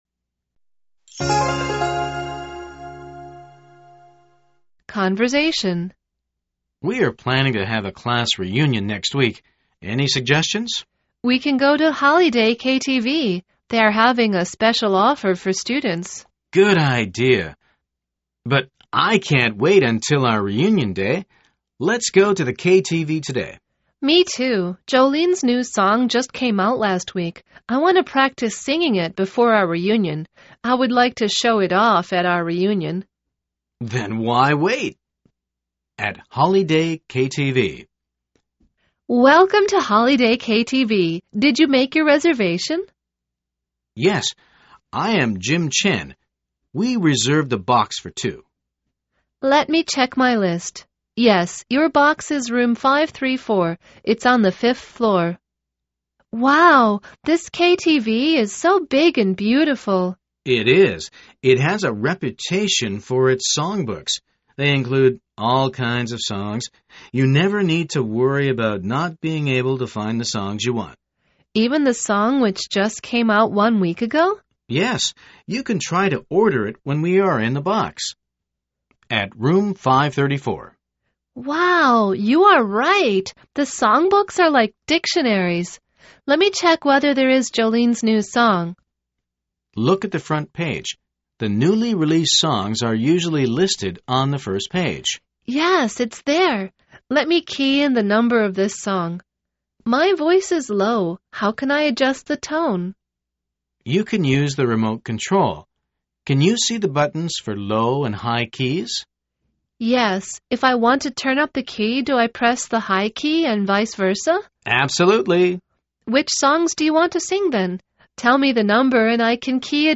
口语会话